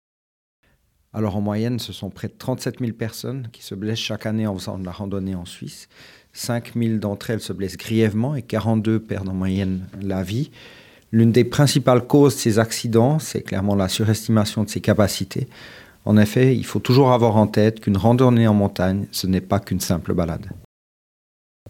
porte-parole